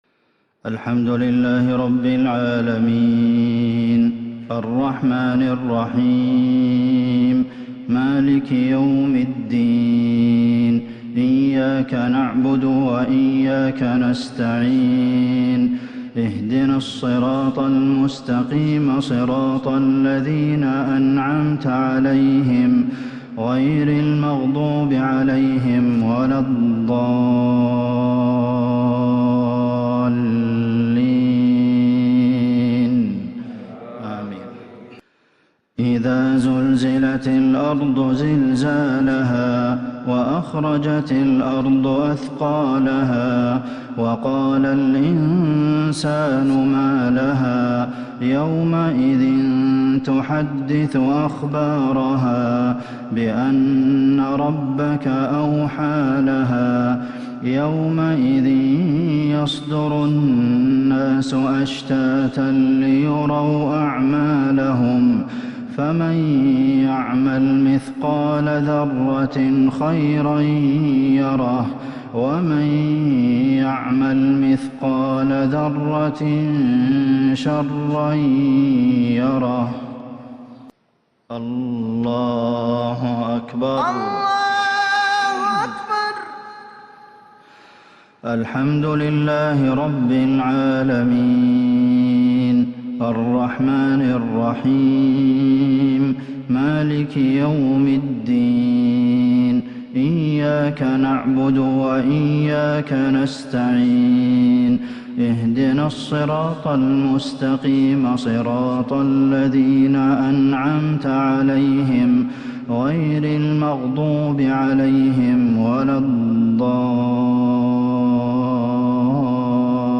مغرب السبت 1-7-1442هـ سورتي الزلزلة والعصر | Maghrib prayer Surah Az-Zalzalah and Al-‘Asr 13/2/2021 > 1442 🕌 > الفروض - تلاوات الحرمين